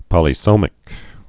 (pŏlē-sōmĭk)